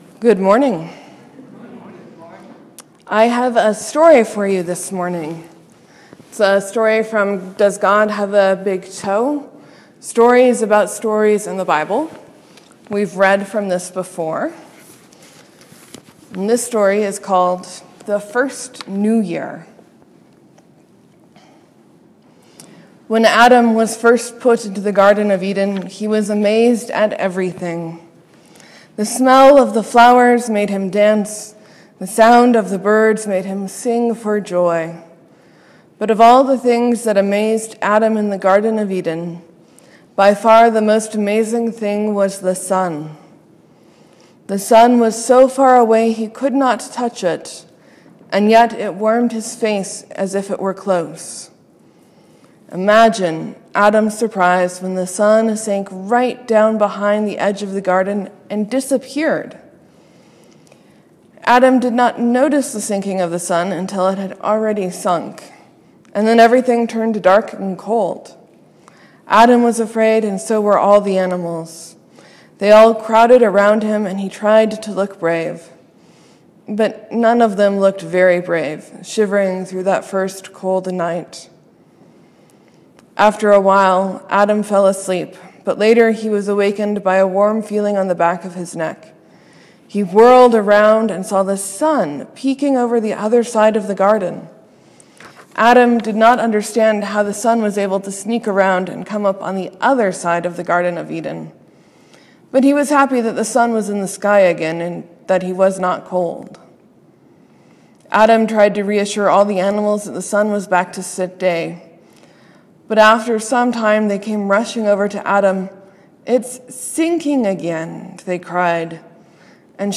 Morsels & Stories: I read a story from Does God have a Big Toe?
Sermon: I ponder my enjoyment of prequels and what would have helped Simon, Andrew, James, and John leave their families, their jobs, and how they’d learned to be faithful.